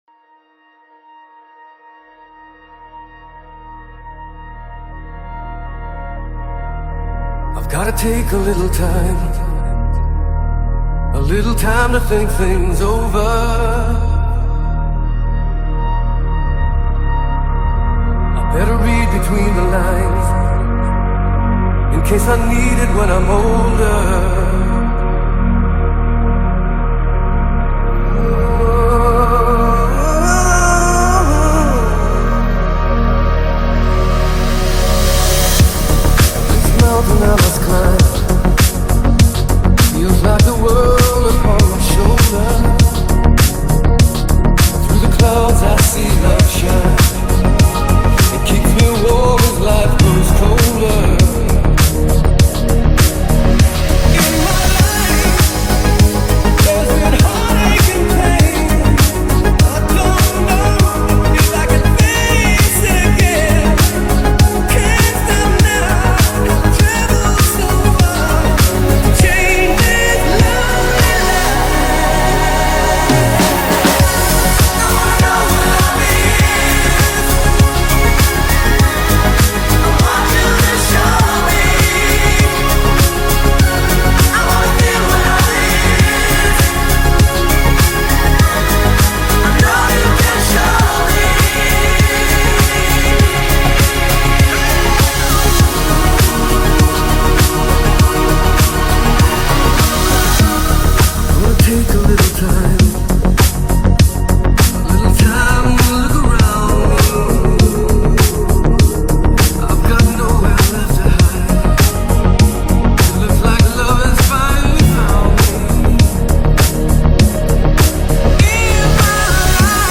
Мощненько так обработали эту известную вещь)